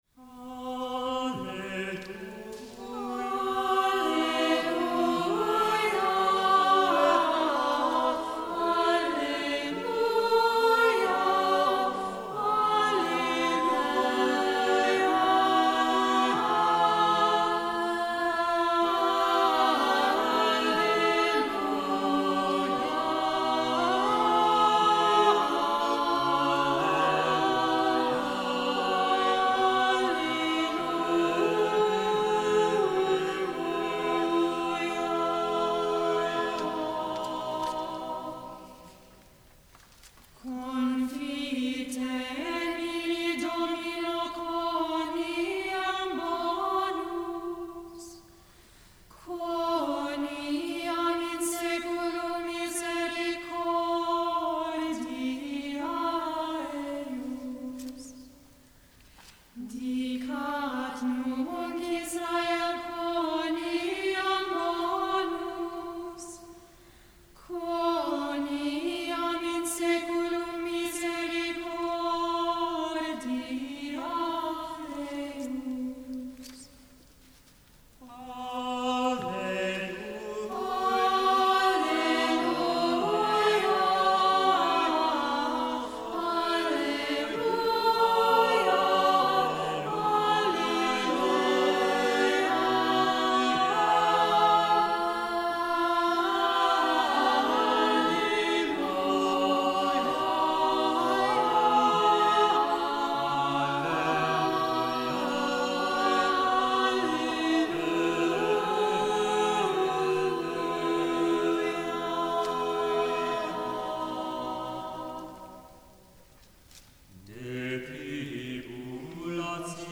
O CELEBRATE THE 500TH YEAR of Giovanni Pierluigi da Palestrina, our parish choirs came together for a special concert honoring one of the greatest composers in the history of sacred music.
Alleluia – Missa Super UT RE MI FA SOL LA (SATB)
This short motet, written for the Mass Proper, sparkles with bright, syllabic writing and joyful rising motives. It captures Palestrina’s lighter “festival” style—dignified, dance-like, and wonderfully accessible for choirs just beginning to explore polyphony.
Live recording • Alleluia, Missa Super